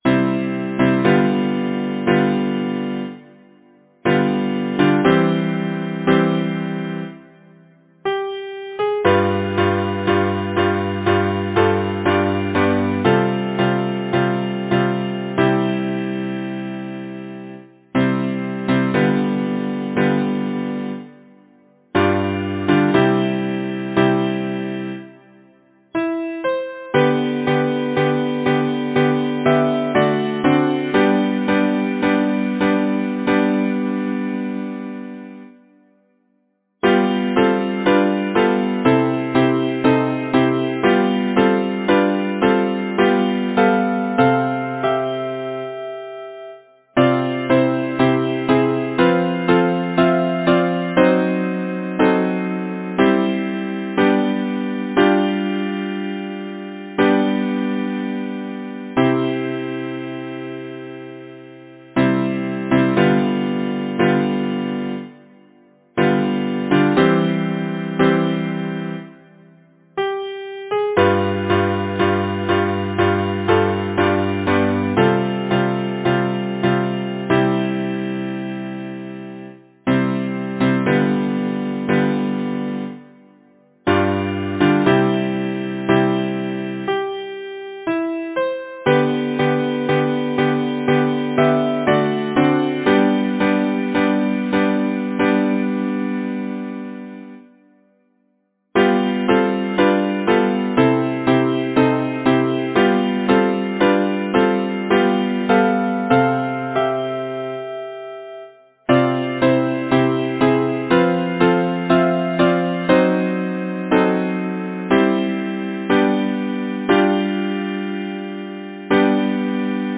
Title: In the moonlight Composer: Kate M. Preston Lyricist: Elizabeth B. Wiswell Platt Number of voices: 4vv Voicing: SATB Genre: Secular, Partsong
Language: English Instruments: A cappella